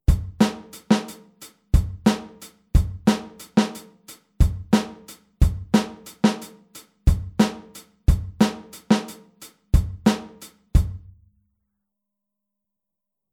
Anders als die Wechselschläge bei 16tel spielt die rechte Hand durchgängig alle 8tel.
Groove05-8tel.mp3